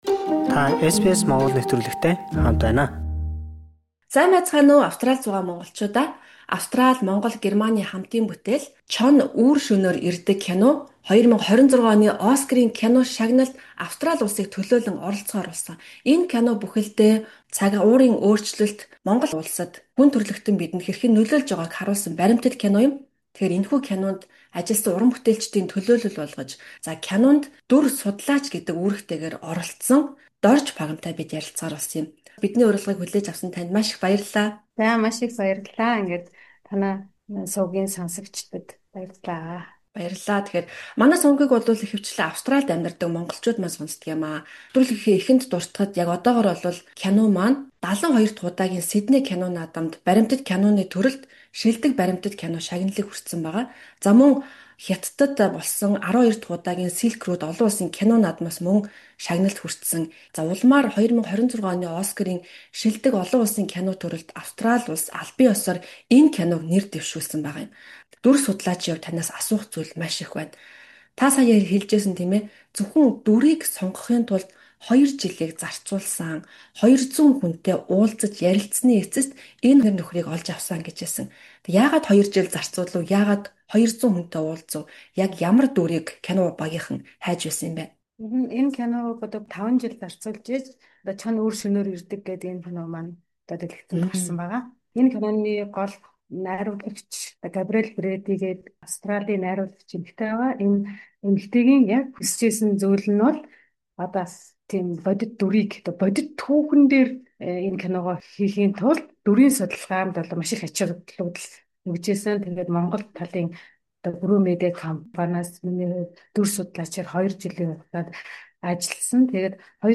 ярилцлаа